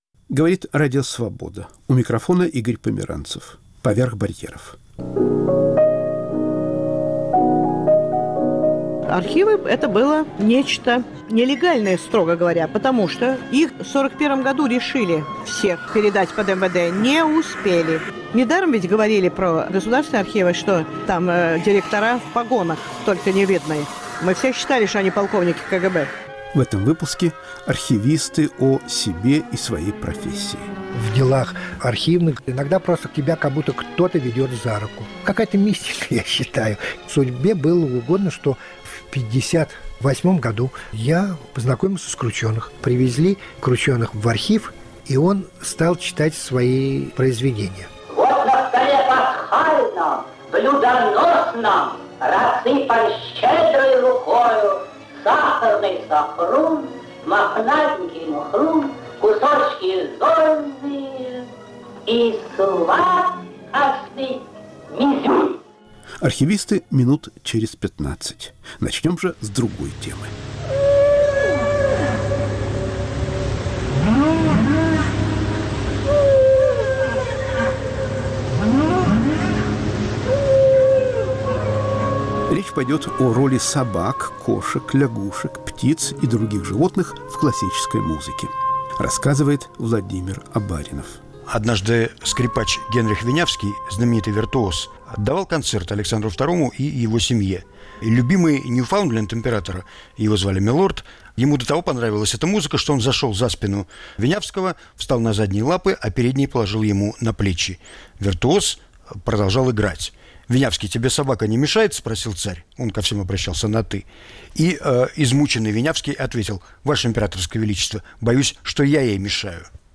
В передаче звучат голоса Алексея Кручёных и Владимира Набокова